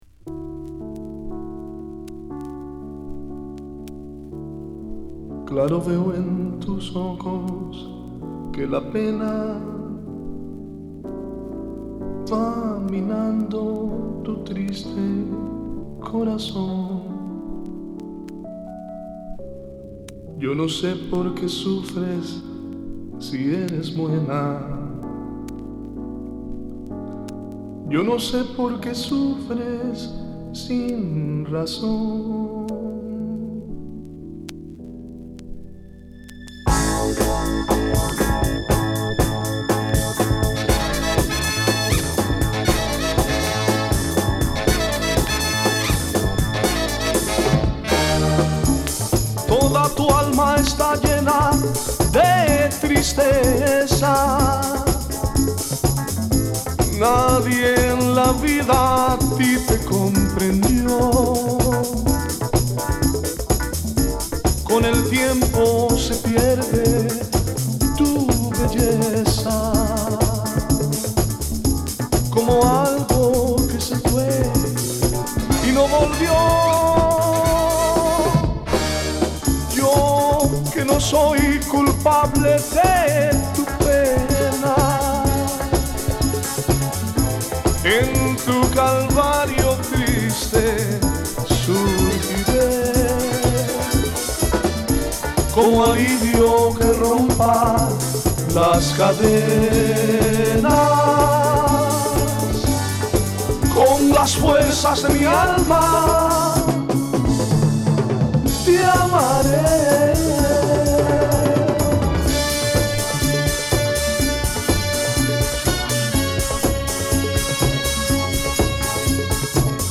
ラテンにソウル、ディスコを加えたクロスオーバーな1枚で
Modern Soul
Salsa